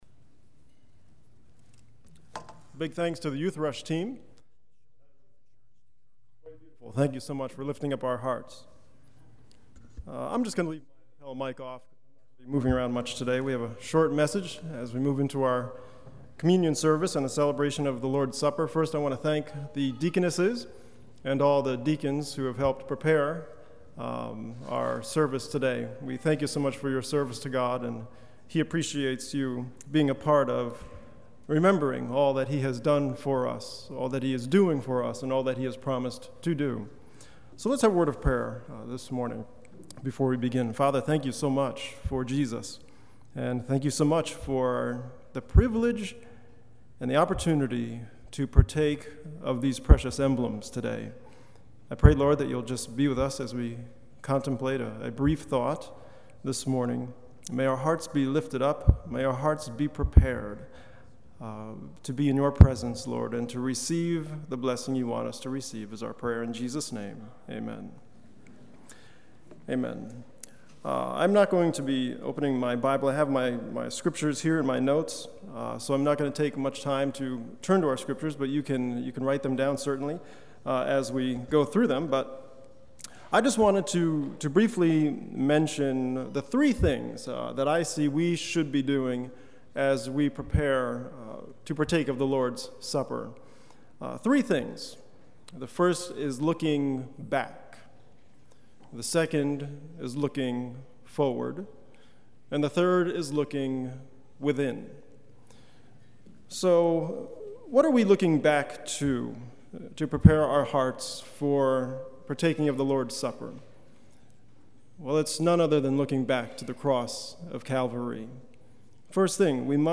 on 2016-06-25 - Sabbath Sermons